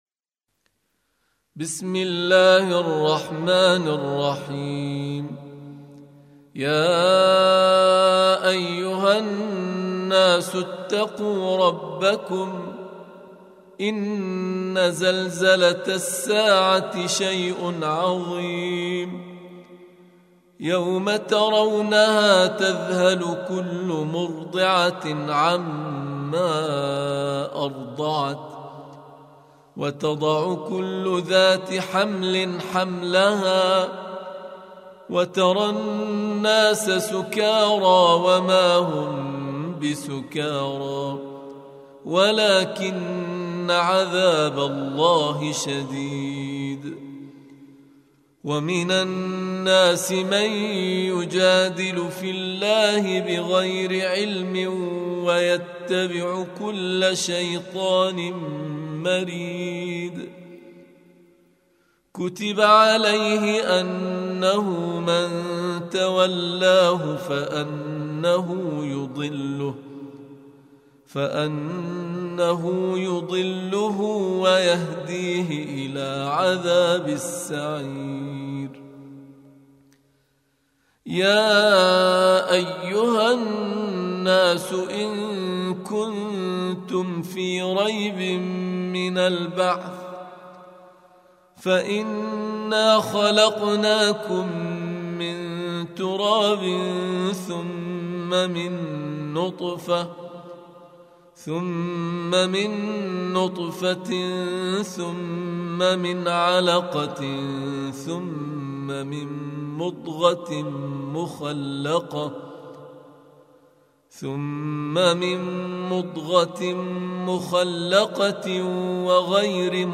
Surah Repeating تكرار السورة Download Surah حمّل السورة Reciting Murattalah Audio for 22. Surah Al-Hajj سورة الحج N.B *Surah Includes Al-Basmalah Reciters Sequents تتابع التلاوات Reciters Repeats تكرار التلاوات